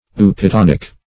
eupittonic - definition of eupittonic - synonyms, pronunciation, spelling from Free Dictionary Search Result for " eupittonic" : The Collaborative International Dictionary of English v.0.48: Eupittonic \Eu`pit*ton"ic\, a. (Chem.)